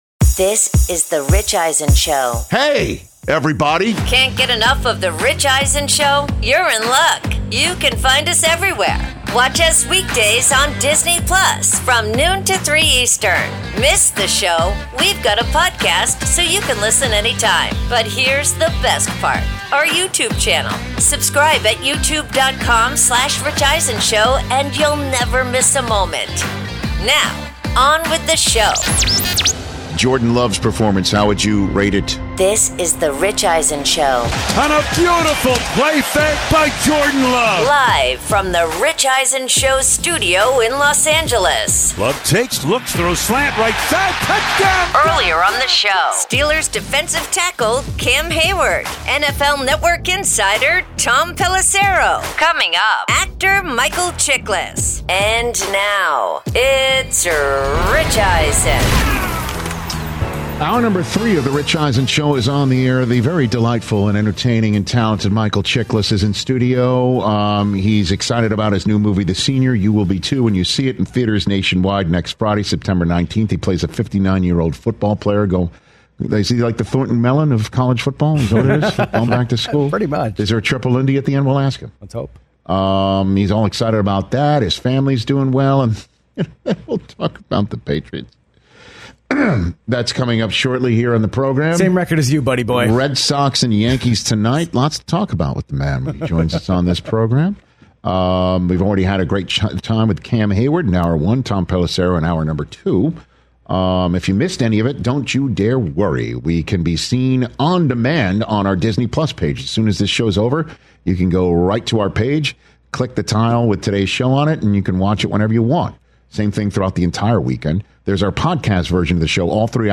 Hour 3: NFL Week 2’s Top 5 Games, plus Actor Michael Chiklis In-Studio